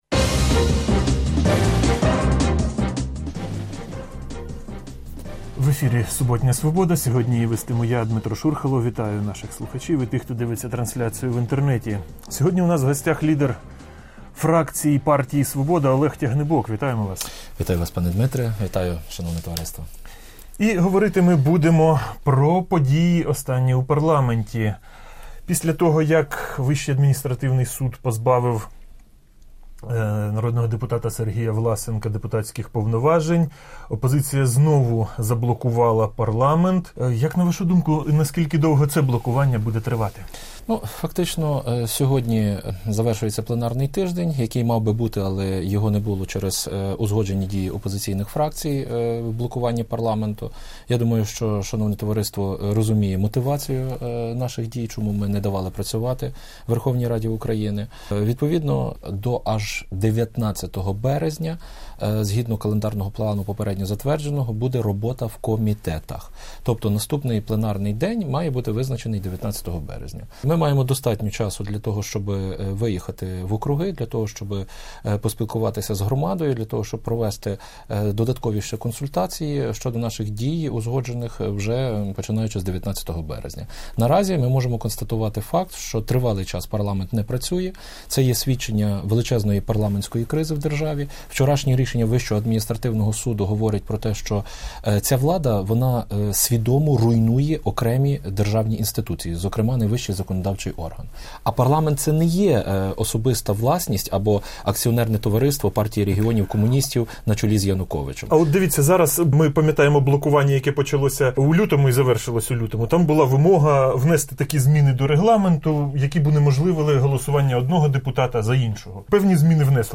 Суботнє інтерв’ю